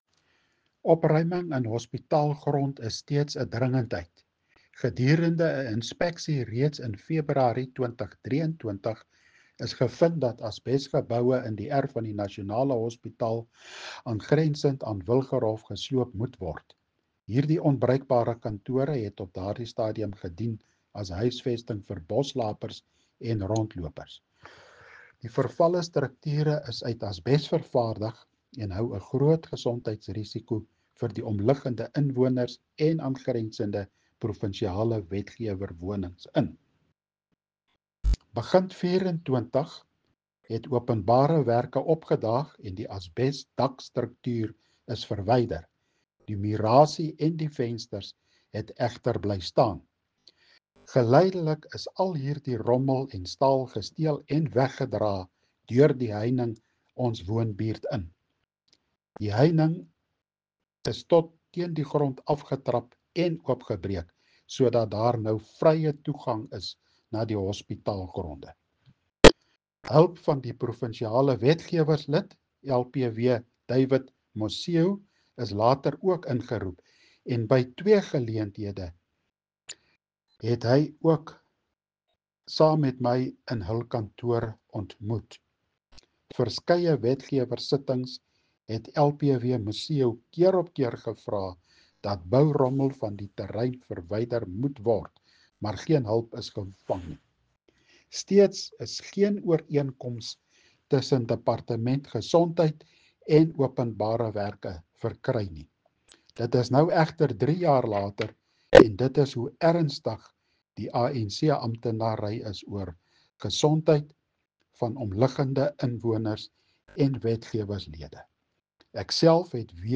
Afrikaans soundbites by Cllr Hennie van Niekerk and